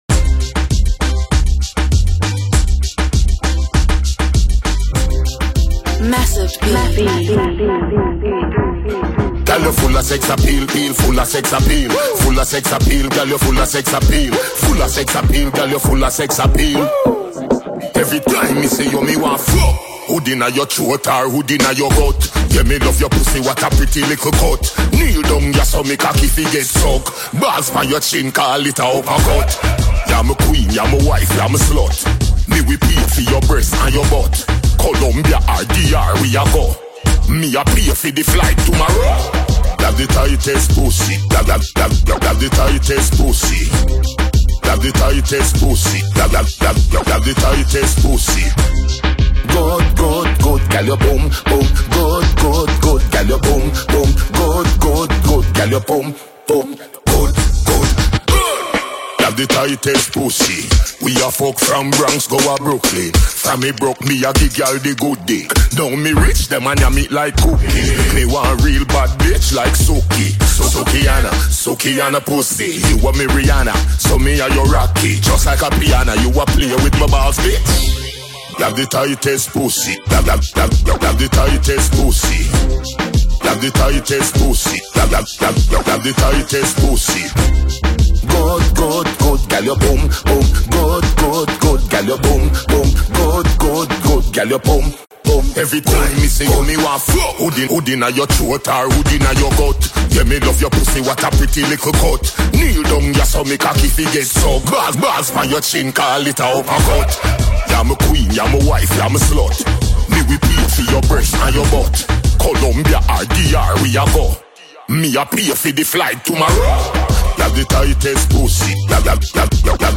DancehallMusic